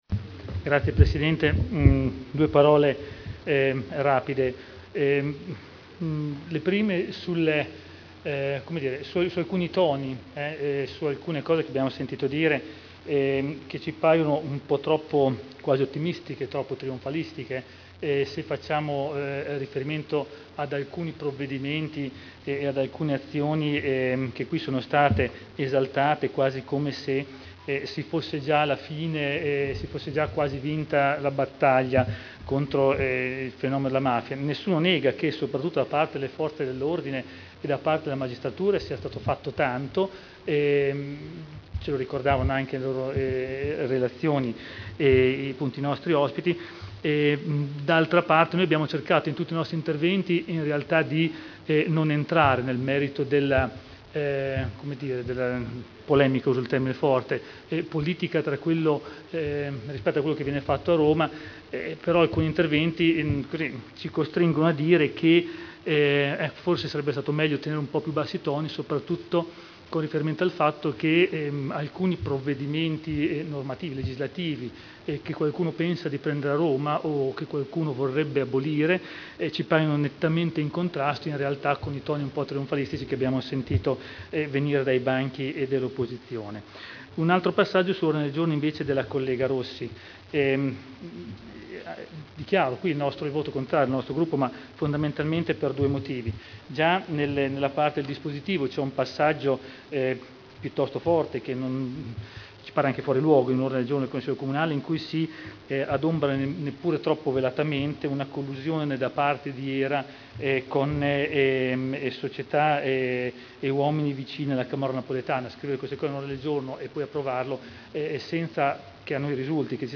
Intervento del Consigliere Gian Domenico Glorioso al Consiglio Comunale su: Politiche di prevenzione e contrasto alle infiltrazioni mafiose.